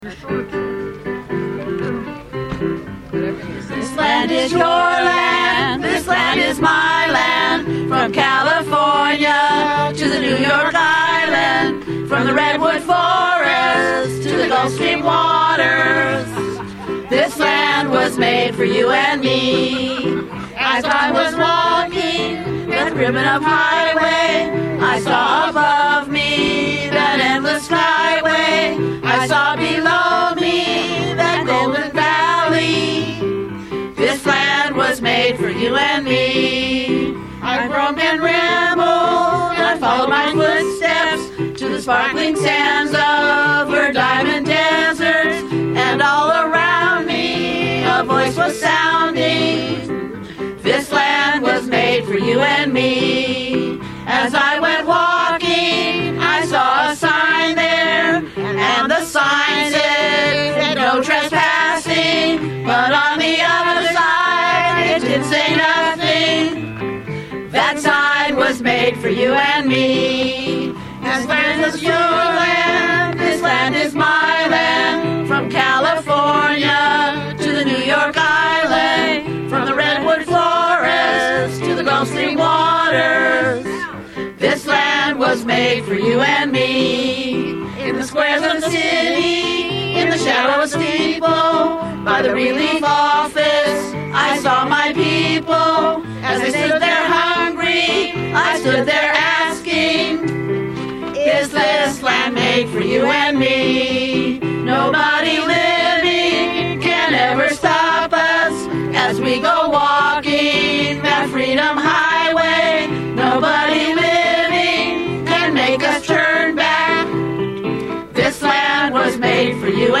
press_conference.mp3